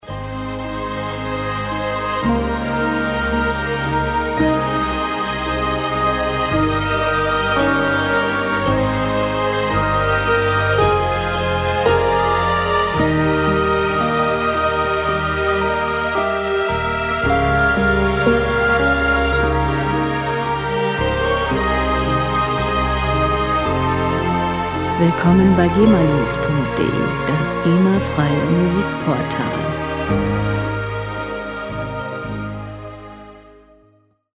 Musikstil: Klassik
Tempo: 56 bpm
Tonart: As-Dur
Charakter: sanft, schön